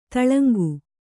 ♪ taḷaŋgu